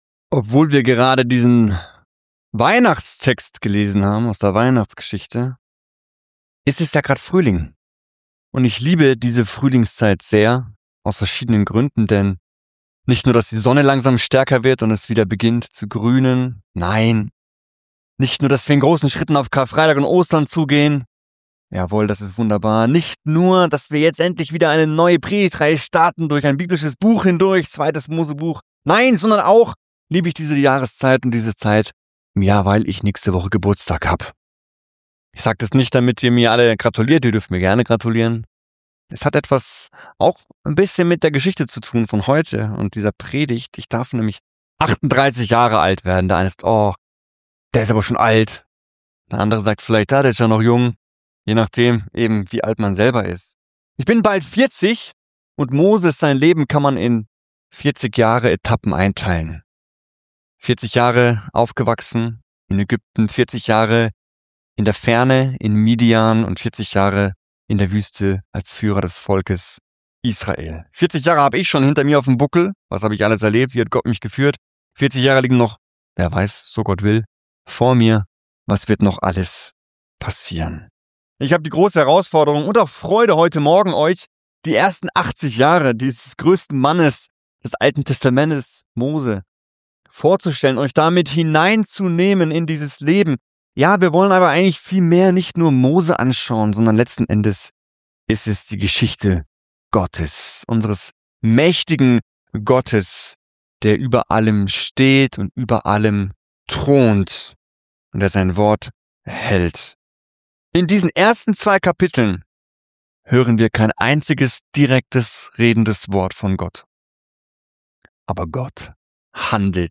Predigtreihe Exodus - Part 1